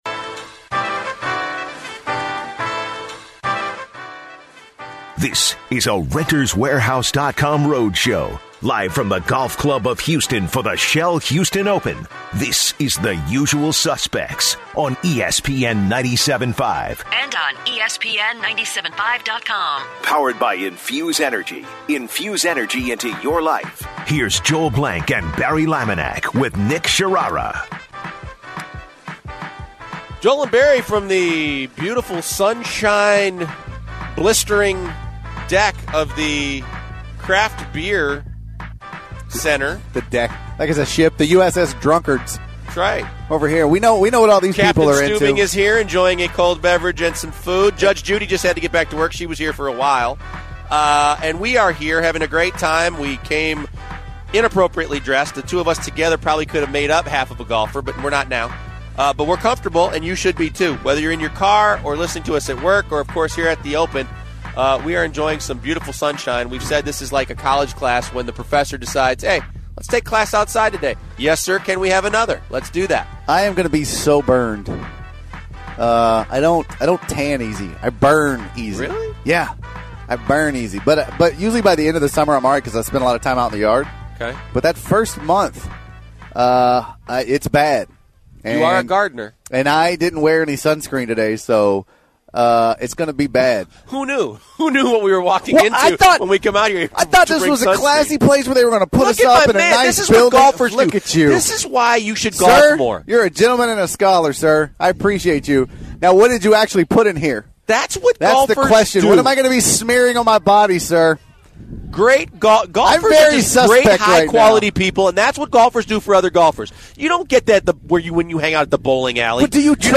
In hour two, the guys continue on the topic on the parent that took a sign to the Cavaliers-Hornets game. The guys react to a listeners call that said Patrick Beverley does not belong in the NBA. Also, they take listerners call on the worst whooping they have received.